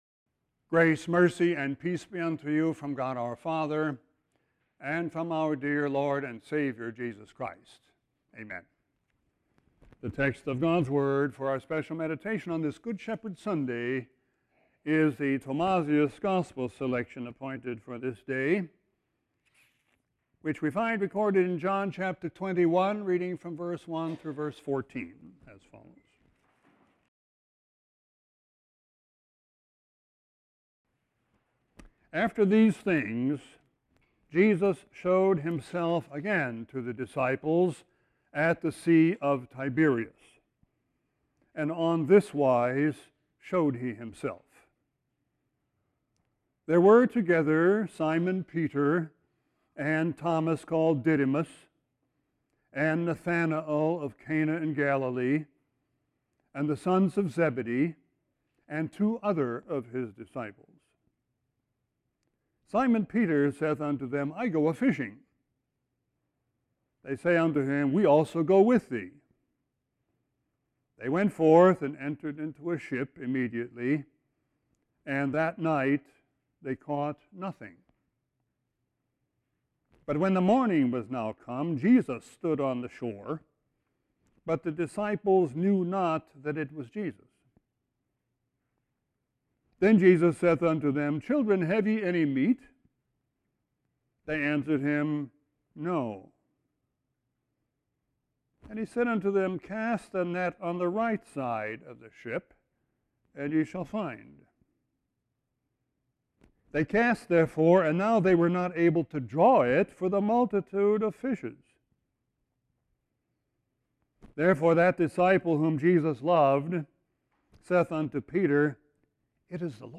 Sermon 4-30-17.mp3